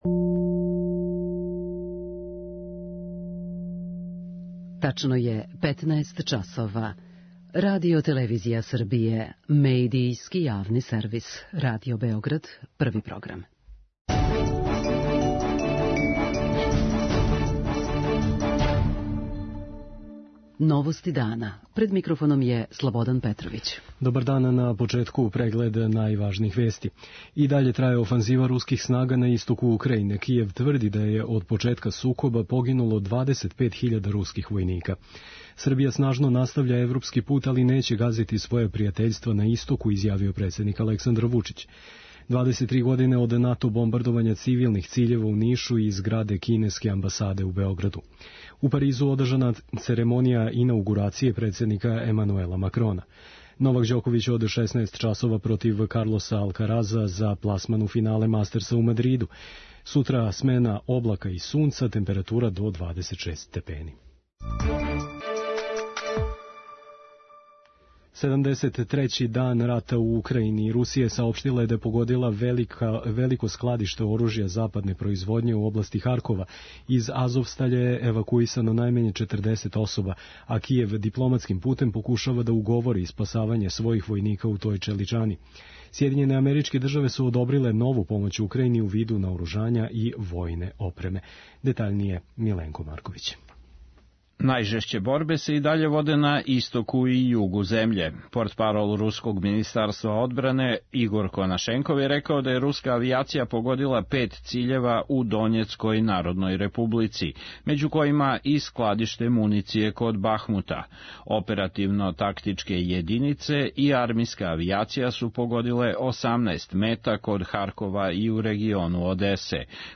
централна информативна емисија